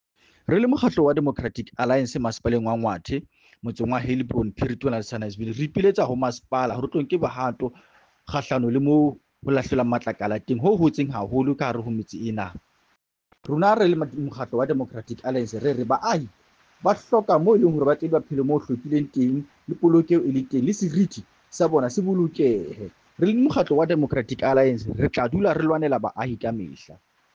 Sesotho soundbites by Cllr Joseph Mbele.